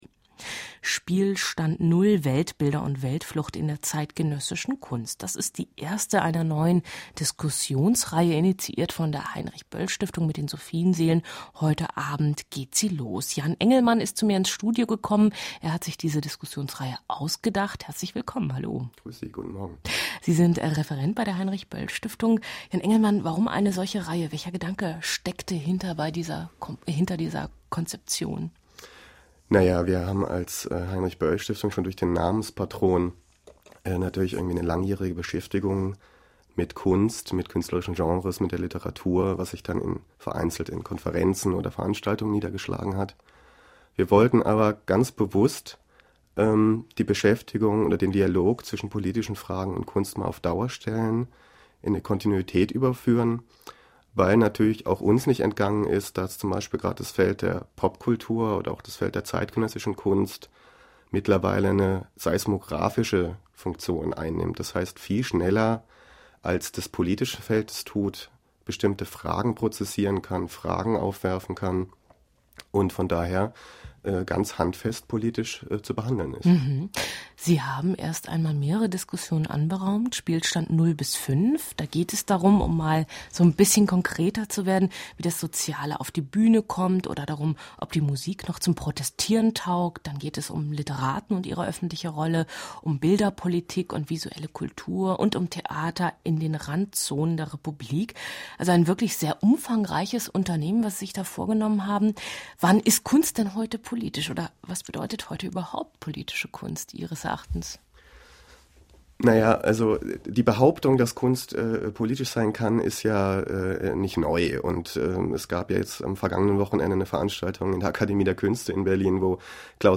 Das Interview vom 31.10.06 aus der Sendung „Wissen“ kann